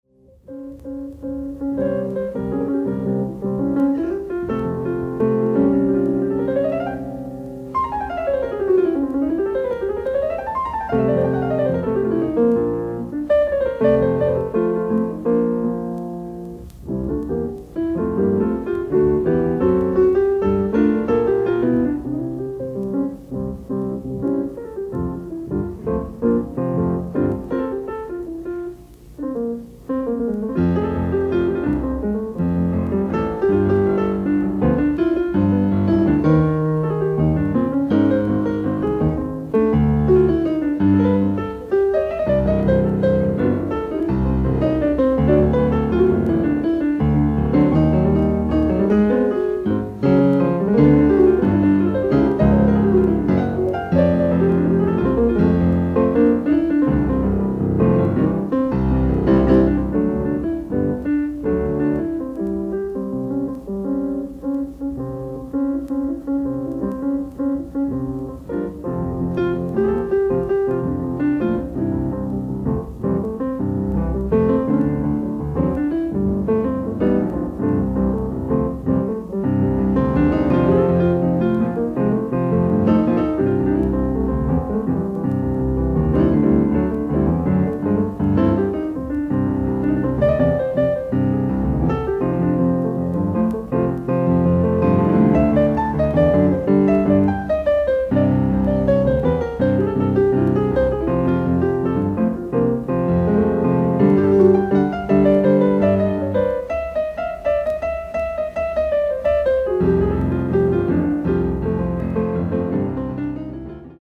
ライブ・アット・ロチェスター、ニュージャージー
※試聴用に実際より音質を落としています。